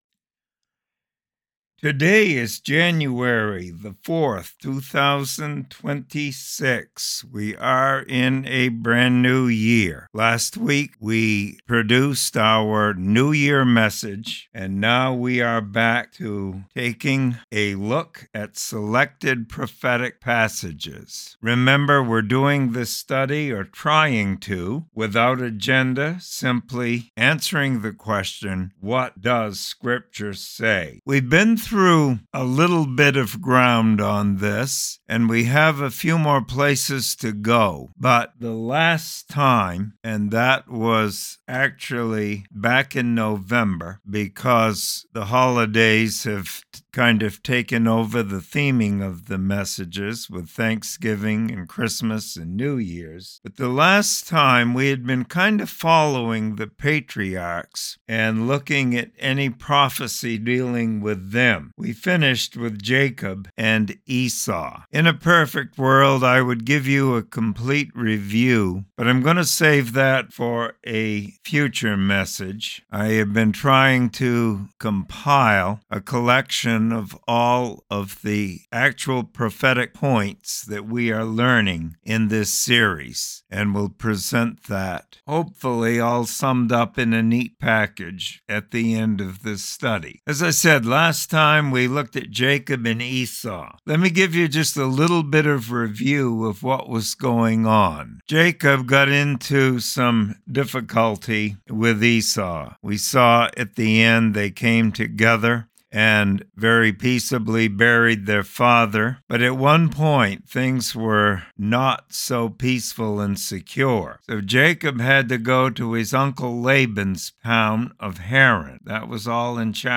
Sermon Library | First Baptist Church of Troy NH